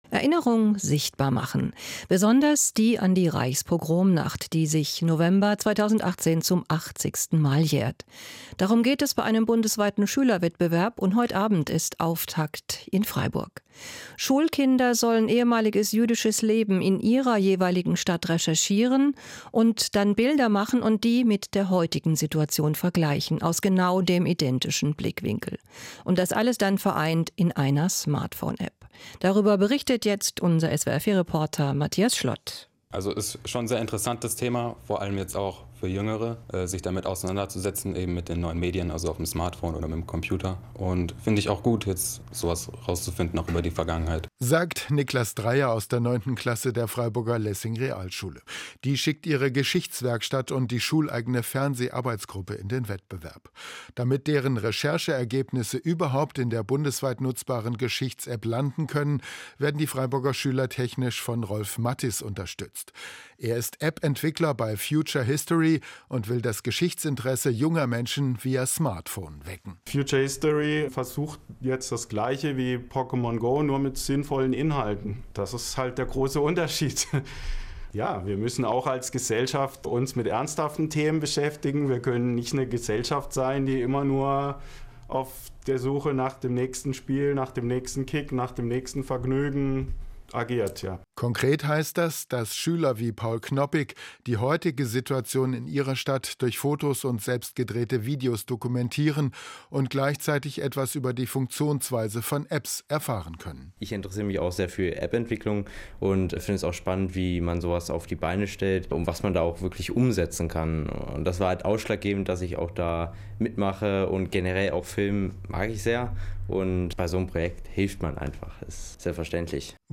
im Hörfunk gesendet.